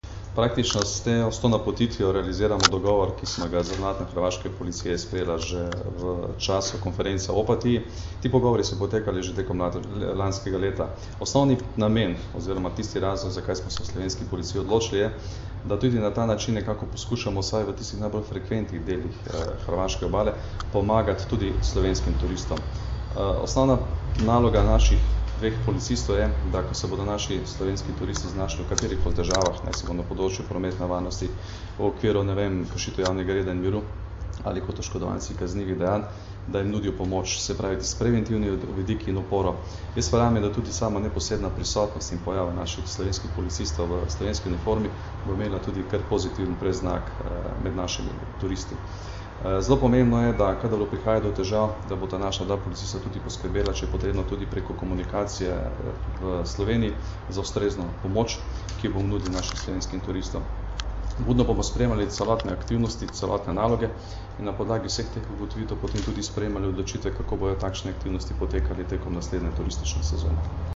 Zvočni zapis izjave Janka Gorška (mp3)